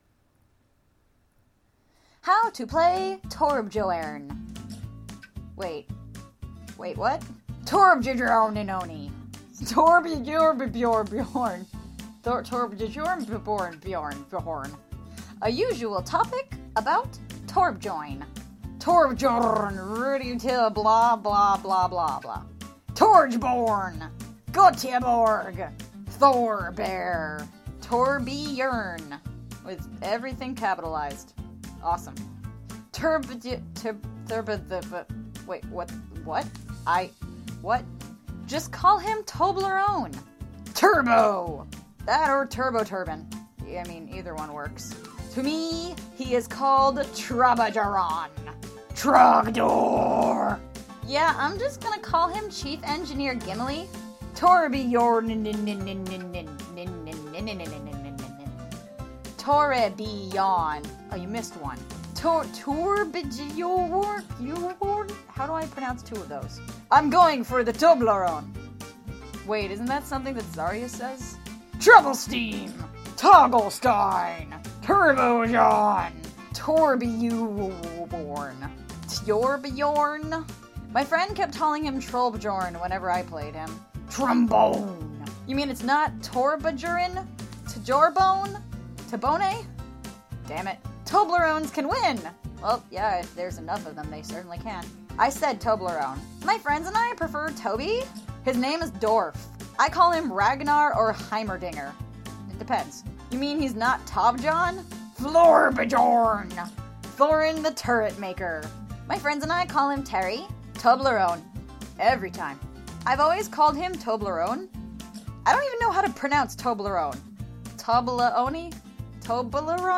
Apparently my last audio post broke somehow so here’s a reuploaded version of how to pronounce Torb’s name.